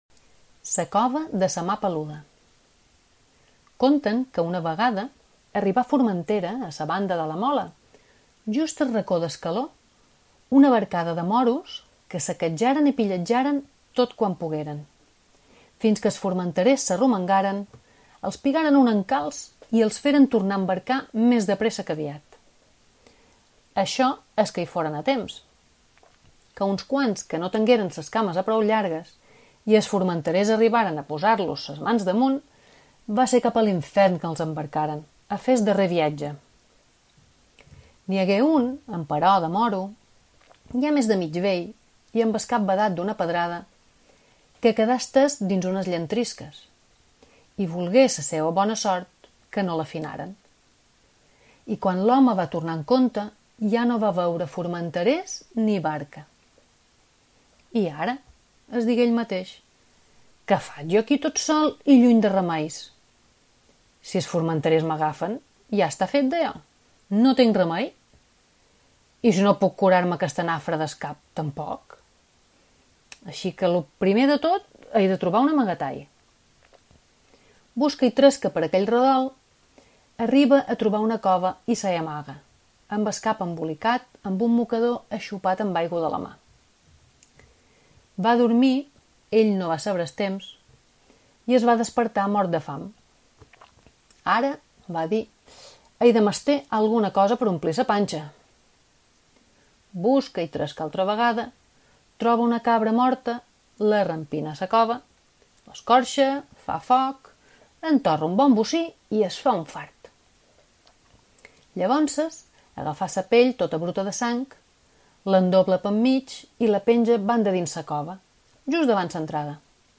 L’espai de Ràdio Illa dirigit als petits i no tan petits de casa, Una ràdio de contes deixa pas durant uns dies als relats tradicionals de Formentera.